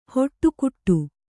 ♪ hoṭṭukuṭṭu